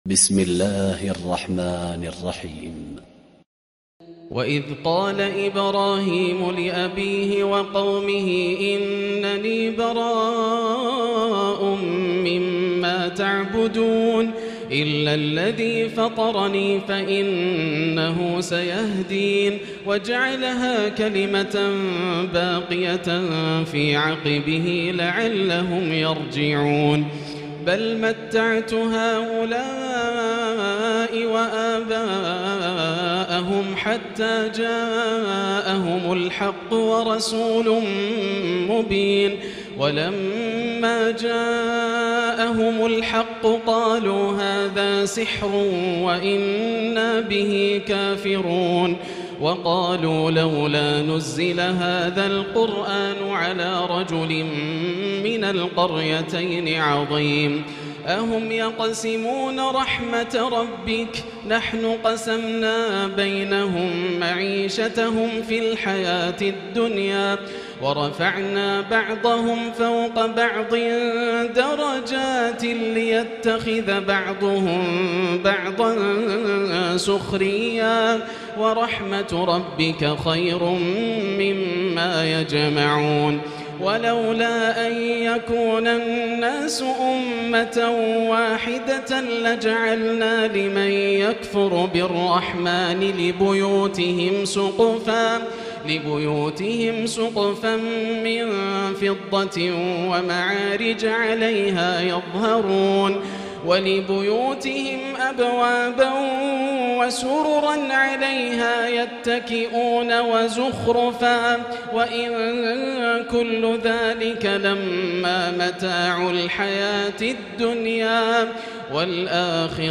الليلة الرابعة والعشرون - ما تيسر من سورة الزخرف من آية 26 وسورتي الدخان والجاثية > الليالي الكاملة > رمضان 1438هـ > التراويح - تلاوات ياسر الدوسري